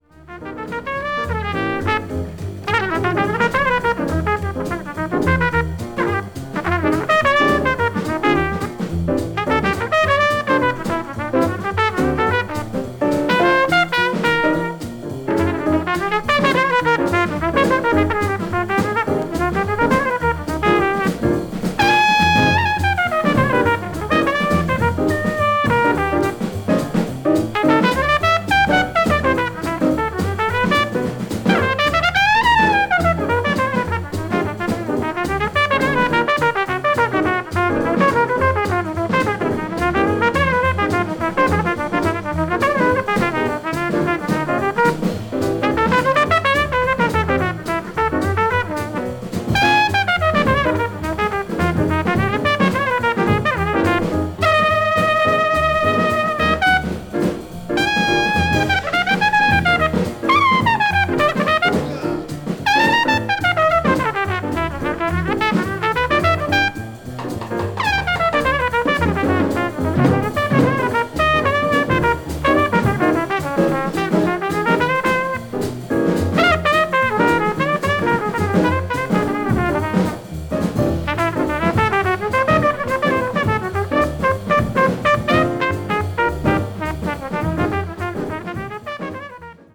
media : EX/EX(わずかにチリノイズが入る箇所あり)
hard bop   modern jazz